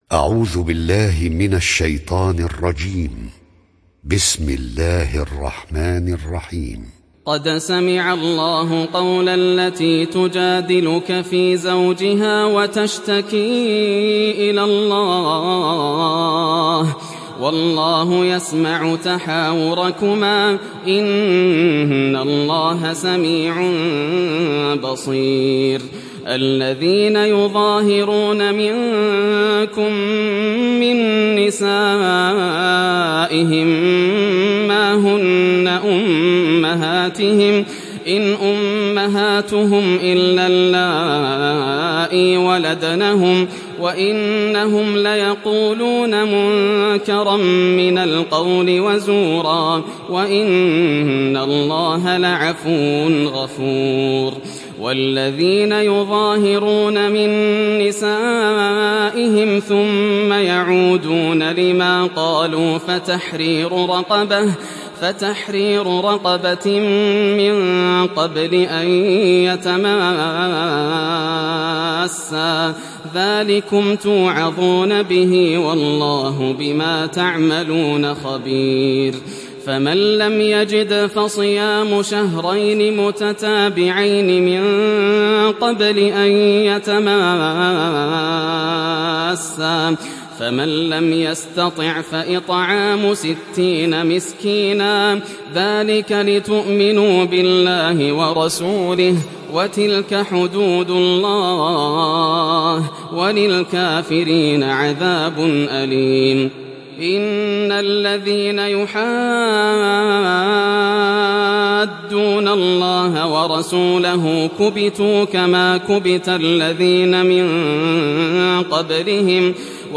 Mücadele Suresi İndir mp3 Yasser Al Dosari Riwayat Hafs an Asim, Kurani indirin ve mp3 tam doğrudan bağlantılar dinle